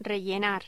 Locución: Rellenar
Sonidos: Voz humana